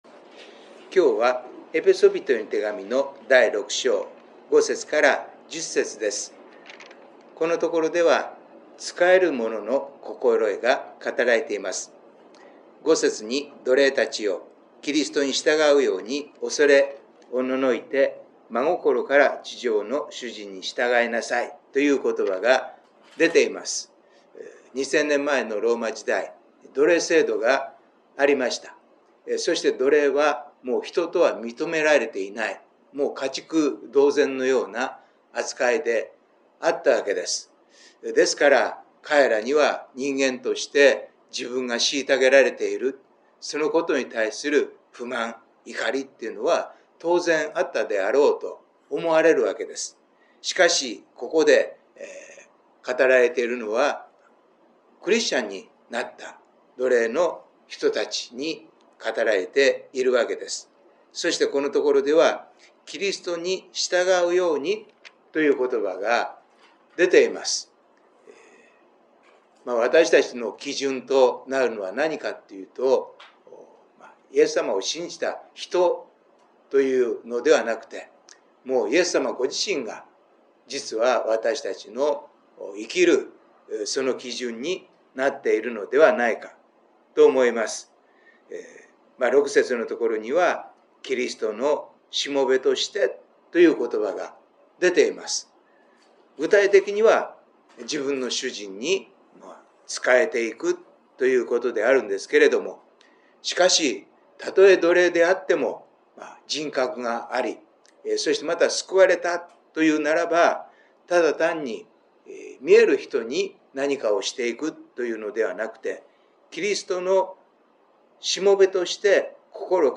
2023/8/9 聖書研究祈祷会